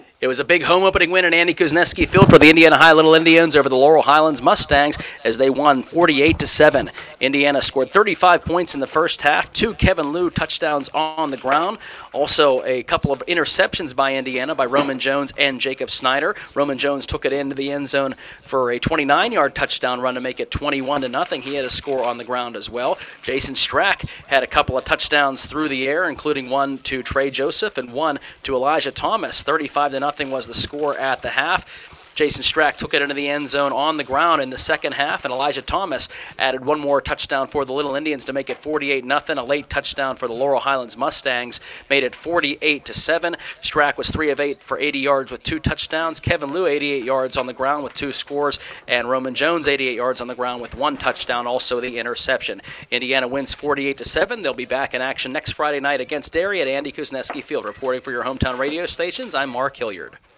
indiana-vs-laurel-highlands-recap-60sec.wav